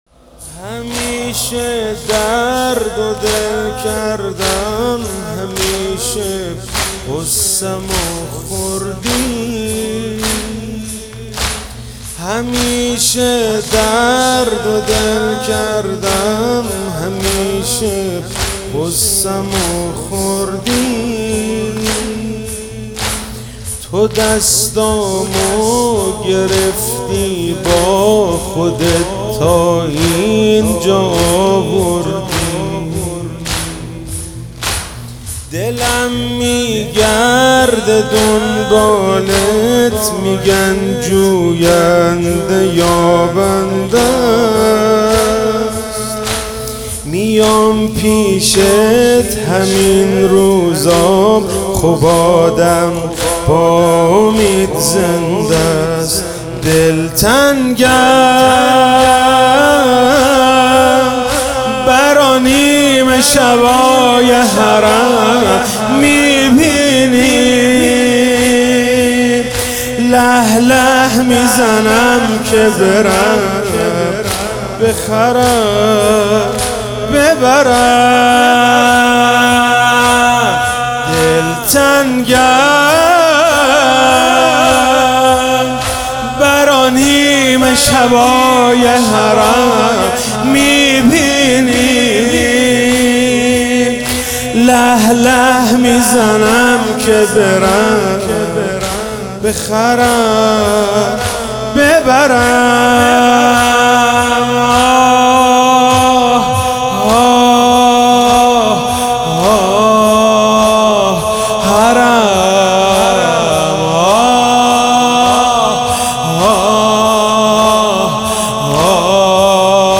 دعای فرج مدح ( کمیت قافیه لنگ و زبان شاعر لال ) روضه روضه ( بار بلا به شانه کشیدم به کودکی ) روضه روضه ( خودم دیدم چجوری تو حرم ... ) روضه ( ای طفل رباب لالایی ) شور ( یه نفر هست که هنوز حالم باهاش خوب میشه ) شور ( سلام همه زندگیم ) شور ( منتظر قیامتم فقط به عشق این که ) شور ( السلام علی الحسین ) شور ( گرگا روی جسمت ... ) شور ( شمر از تو گودال ) واحد ( نخواستی بدیمو بیاری به روم ) واحد ( ای پنجمین امام که معصوم هفتمی ) زمینه ( همیشه درد و دل کردم ...